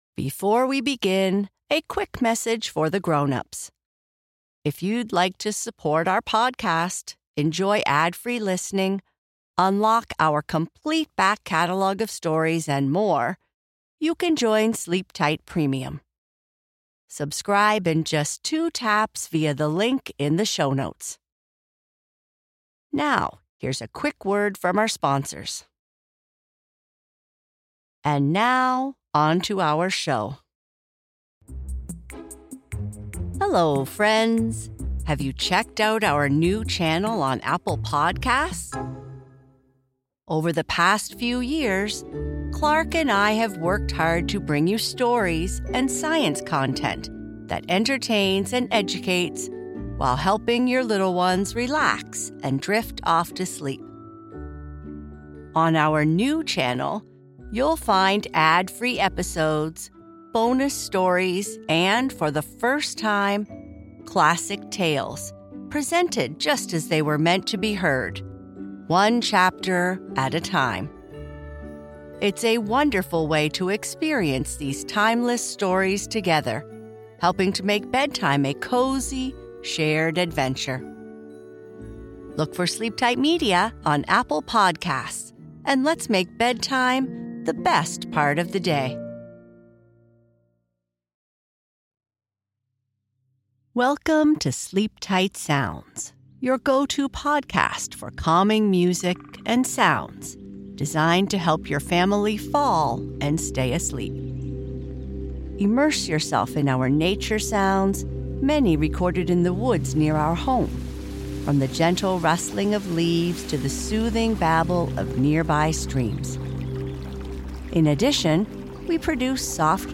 This calming podcast features tranquil music and soothing sounds designed to help children and their families fall asleep and stay asleep.